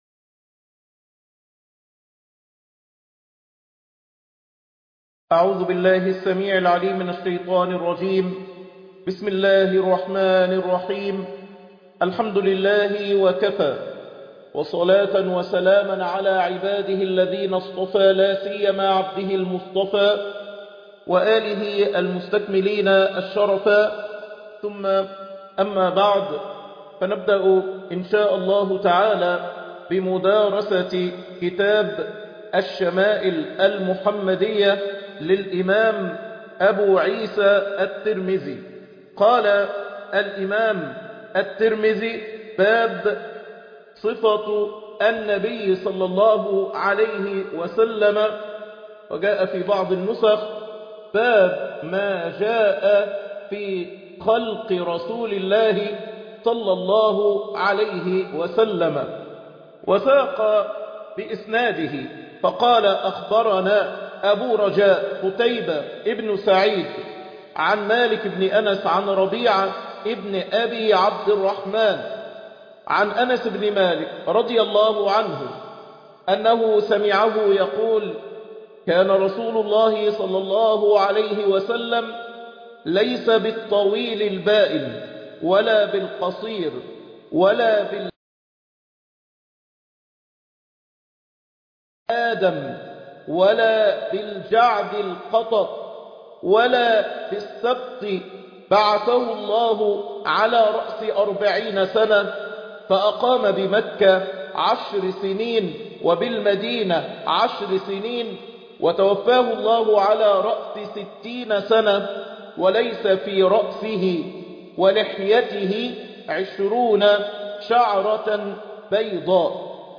شرح كتاب الشمائل المحمدية الدرس الثاني كأنك تراه صلى الله عليه وسلم